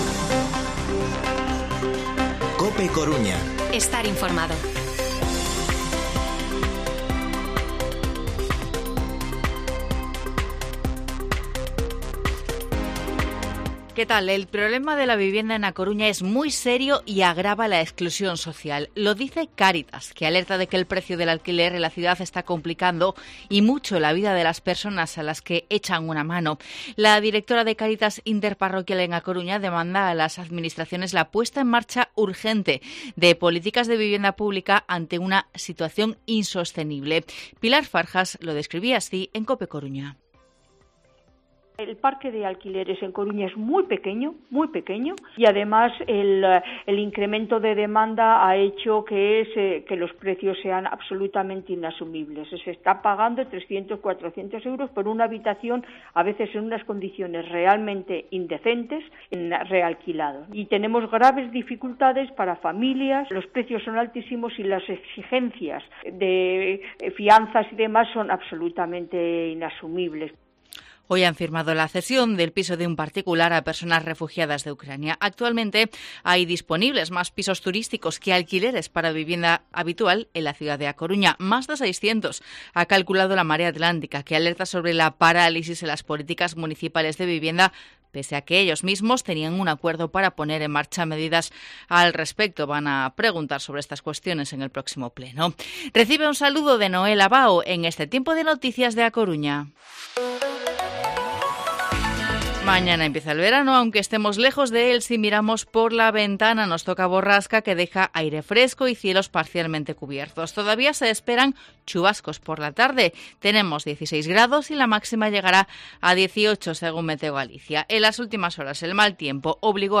Informativo Mediodía COPE Coruña lunes, 20 de junio de 2022 14:20-14:30